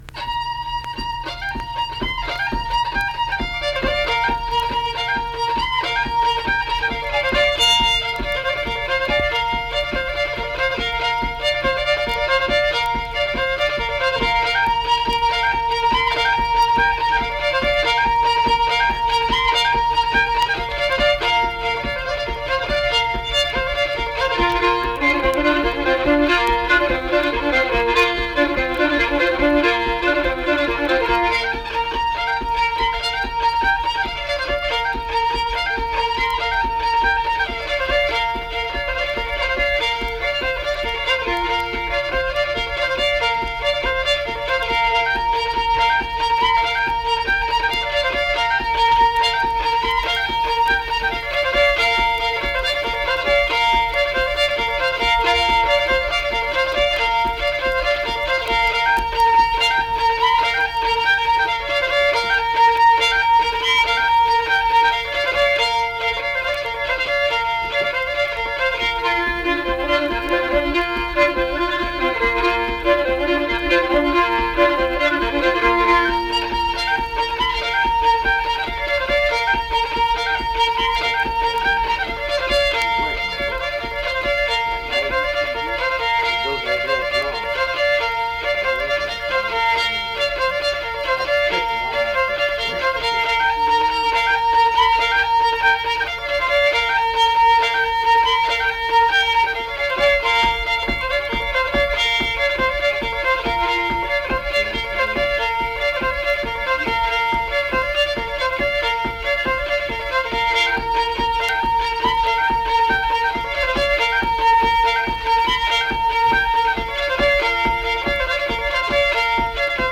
Unaccompanied fiddle music
Instrumental Music
Fiddle
Pocahontas County (W. Va.), Marlinton (W. Va.)